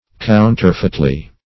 counterfeitly - definition of counterfeitly - synonyms, pronunciation, spelling from Free Dictionary Search Result for " counterfeitly" : The Collaborative International Dictionary of English v.0.48: Counterfeitly \Coun"ter*feit`ly\, adv.